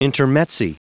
Prononciation du mot intermezzi en anglais (fichier audio)
Prononciation du mot : intermezzi